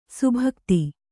♪ subhakti